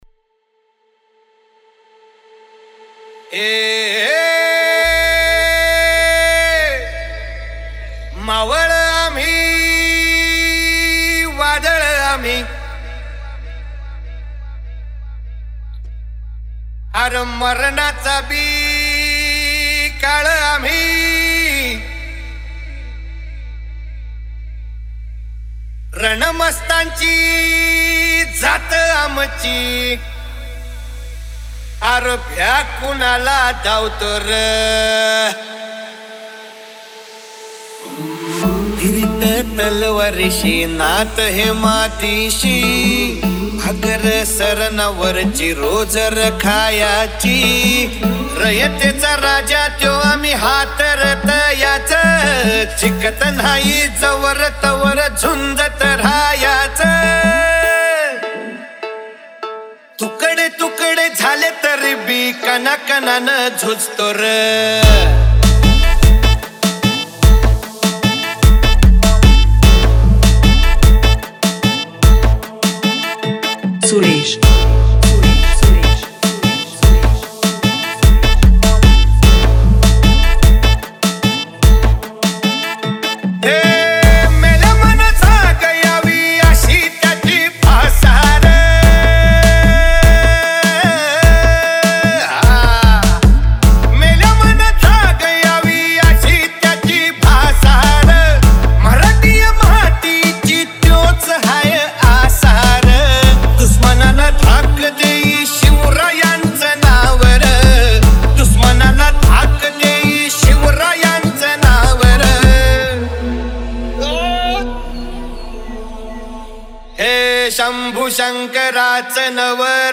Marathi Dj Single 2025
Marathi Sound Check 2025